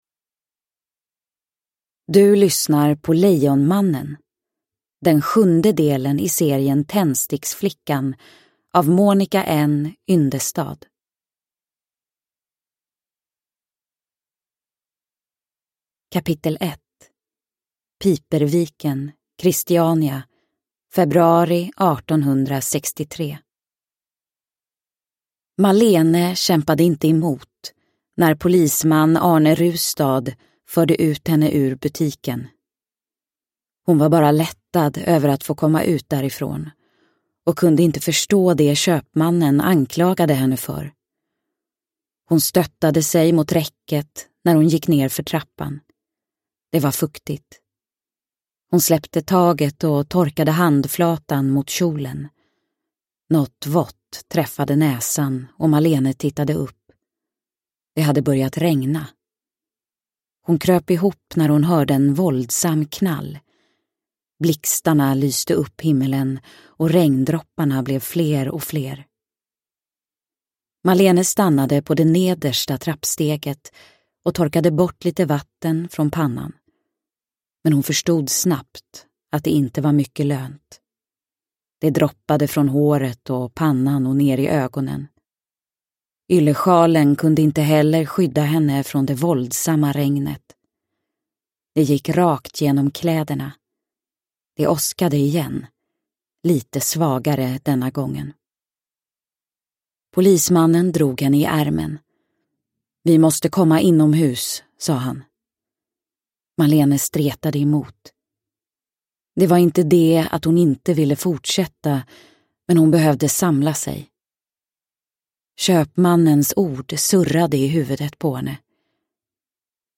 Lejonmannen – Ljudbok – Laddas ner
Uppläsare: Tove Edfeldt